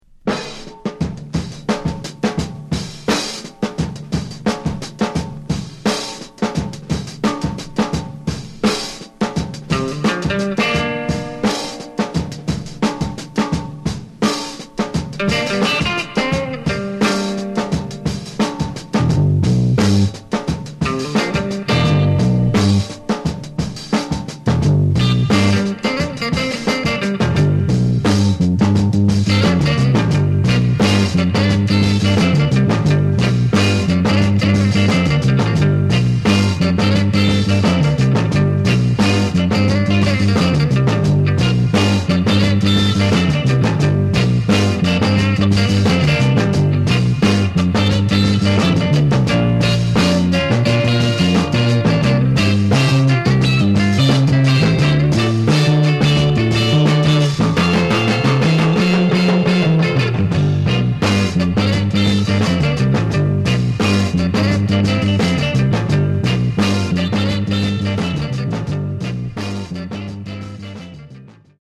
Genre: Funk/Hip-Hop/Go-Go
Delicious early funk track from Detroit